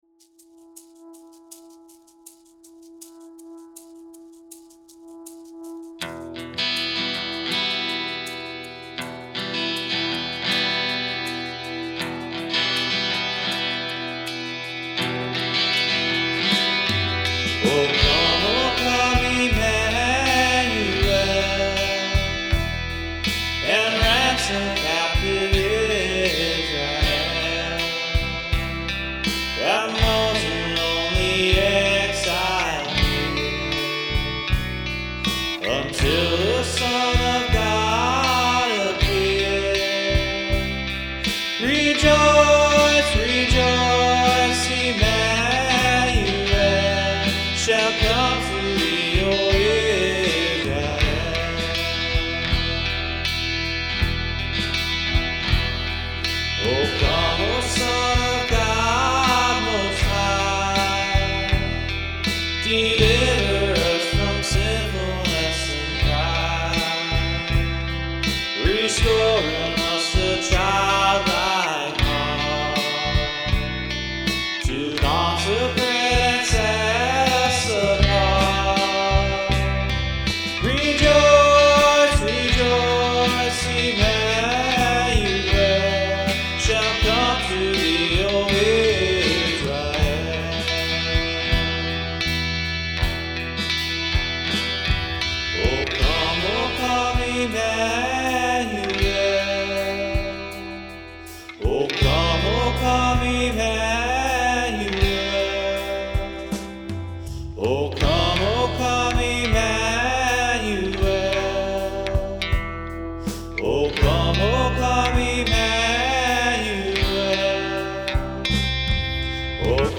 LISTEN TO THE SOME CHRISTMAS FAVOURITES RECORDED BY OUR WORSHIP TEAM!